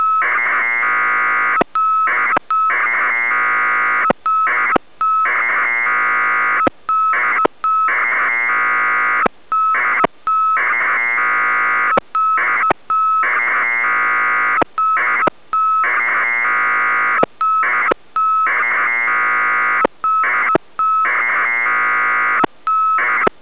Радиомодем